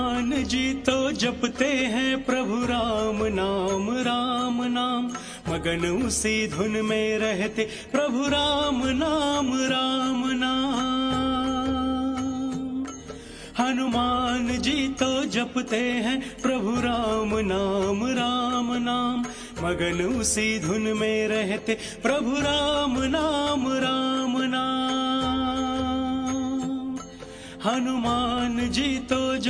CategoryDevotional Ringtones